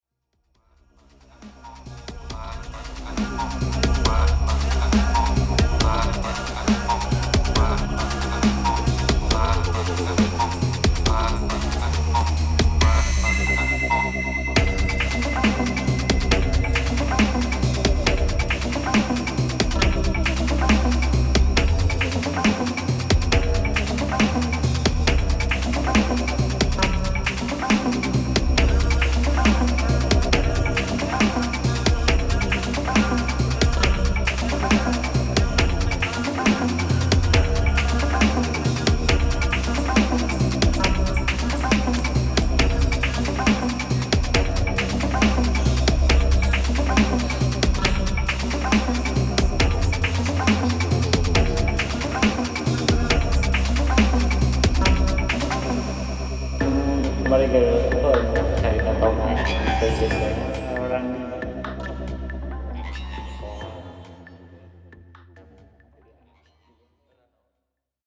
今回もガムラン的なパーカッションアレンジに血湧き肉躍る熱帯生まれの新型インダストリアルを展開。